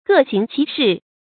gè xíng qí shì
各行其是发音
成语正音 其，不能读作“qī”。